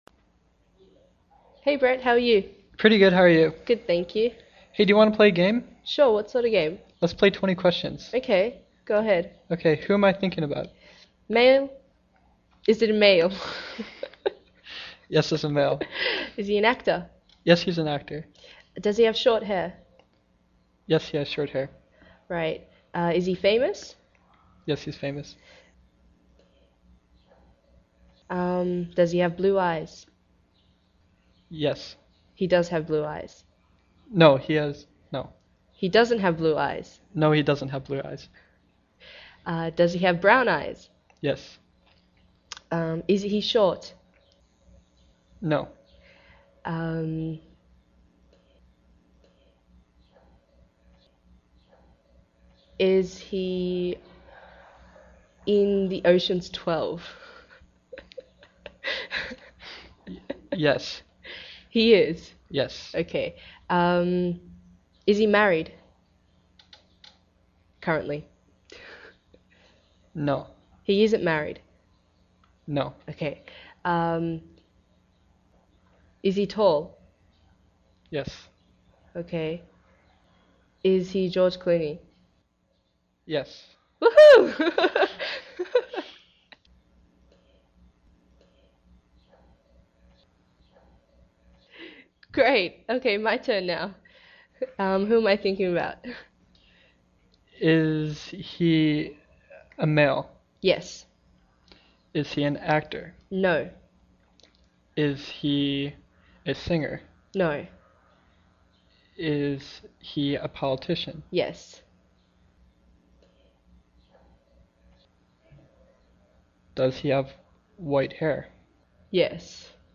英语初级口语对话正常语速08：20个问题（mp3+lrc）